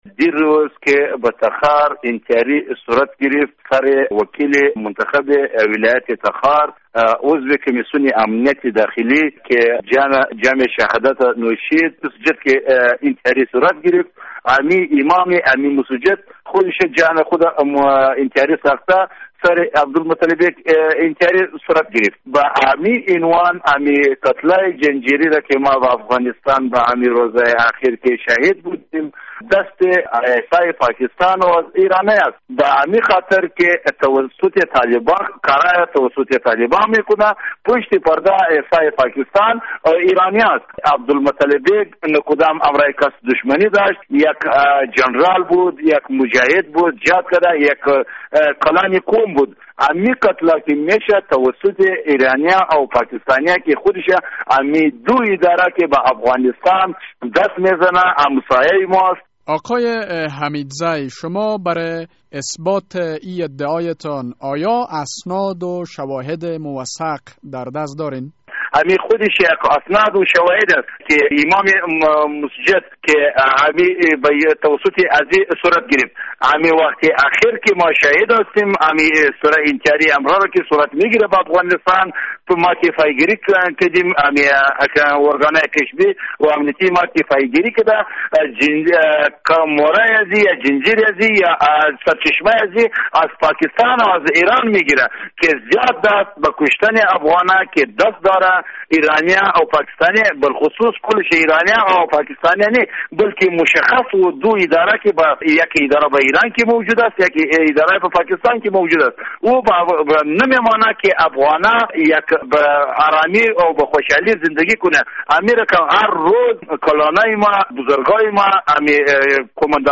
مصاحبه: کی ها در قتل های زنجیره یی افغانستان دست دارند